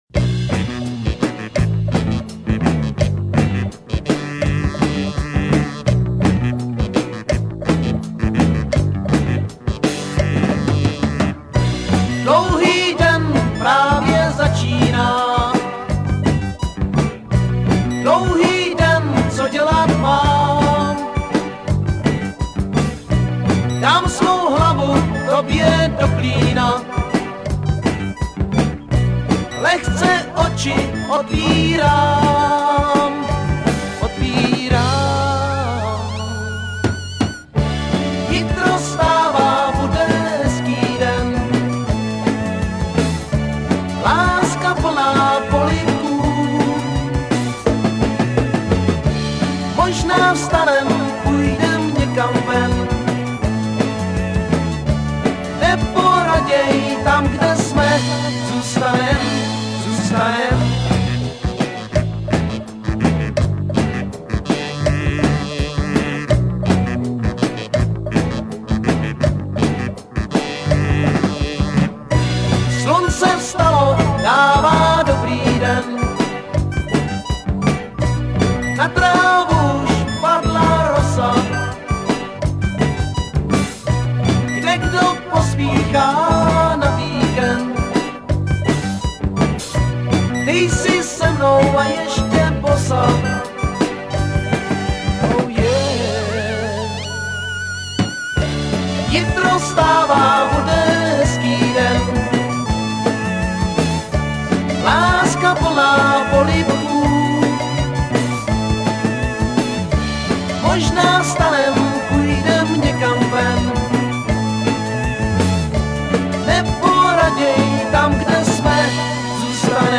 kl�vesy
jednodu���ch, p��mo�ar�ch a melodick�ch p�sni�ek